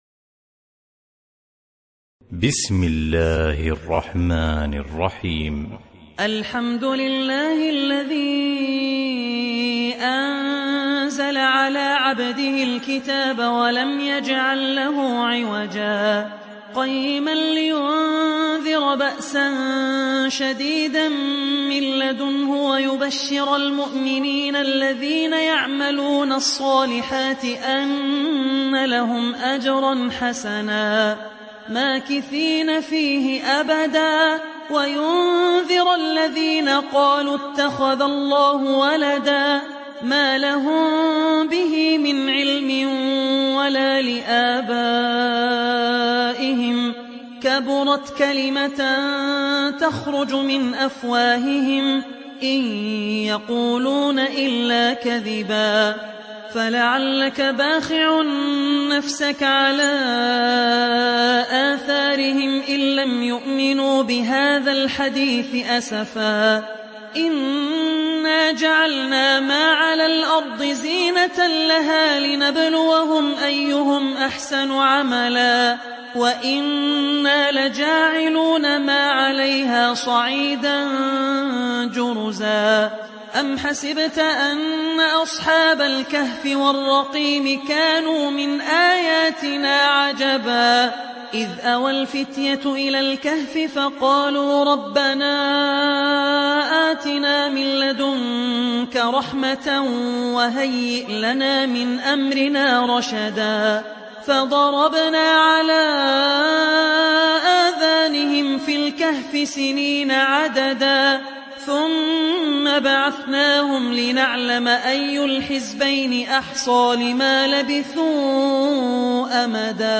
Kehf Suresi İndir mp3 Abdul Rahman Al Ossi Riwayat Hafs an Asim, Kurani indirin ve mp3 tam doğrudan bağlantılar dinle